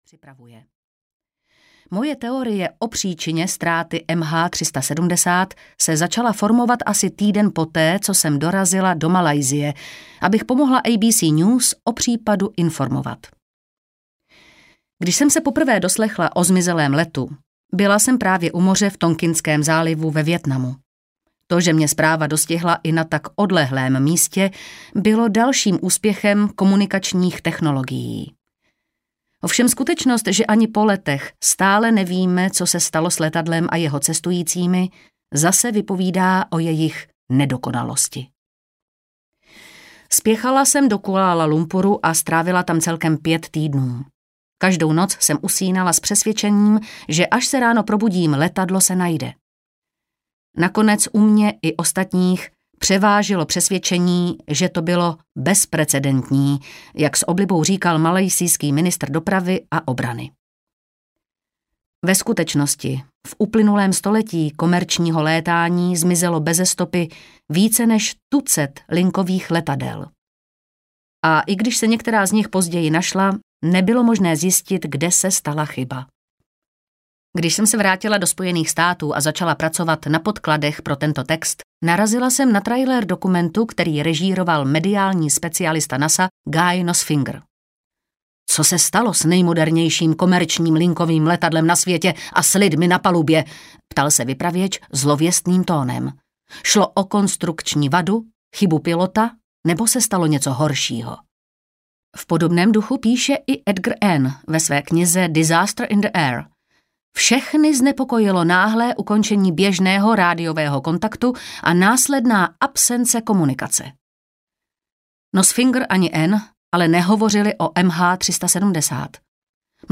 Letecké katastrofy audiokniha
Ukázka z knihy